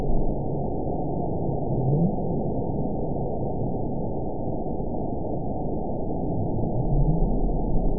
event 922124 date 12/26/24 time 22:49:24 GMT (11 months, 1 week ago) score 9.16 location TSS-AB04 detected by nrw target species NRW annotations +NRW Spectrogram: Frequency (kHz) vs. Time (s) audio not available .wav